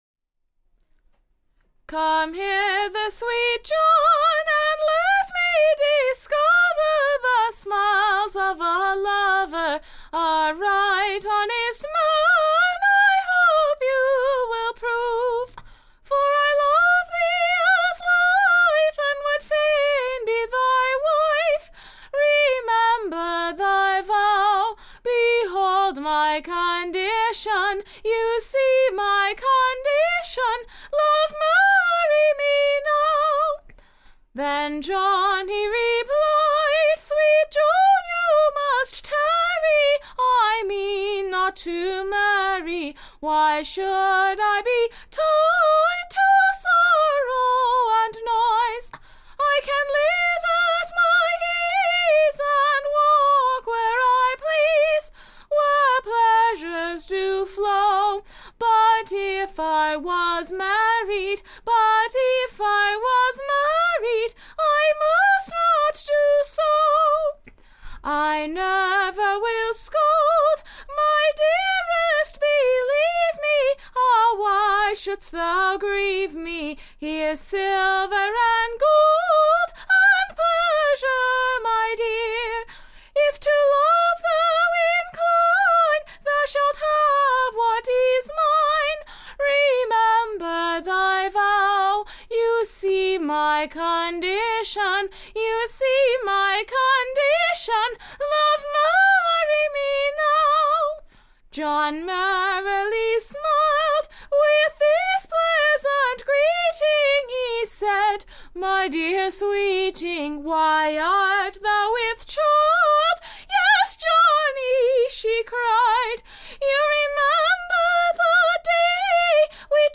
Recording Information Ballad Title Joan's sorrowfull Lamentation: / OR, / False-hearted John's Unkindness to her, at her / time of Distress.